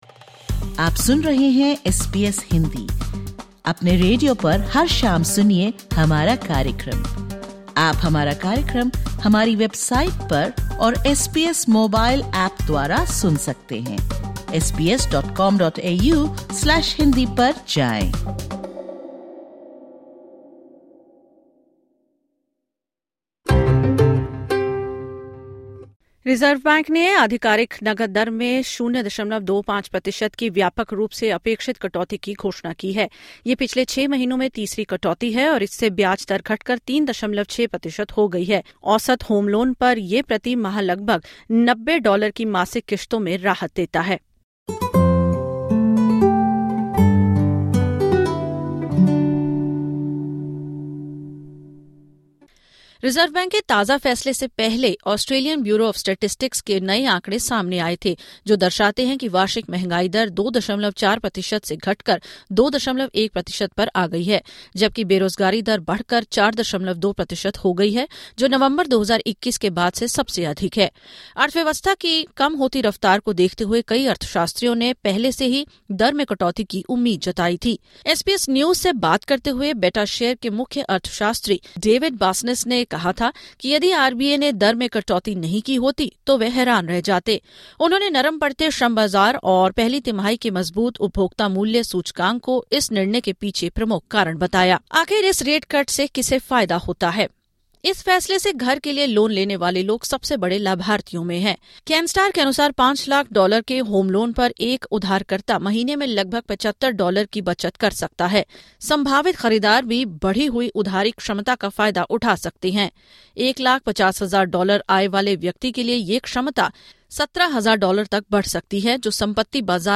The Reserve Bank has delivered the expected interest rate cut, lowering the rate by 0.25 percentage points to 3.6 per cent. In this podcast, we speak with an expert about when homeowners can expect these cuts to take effect and the best time to consider refinancing.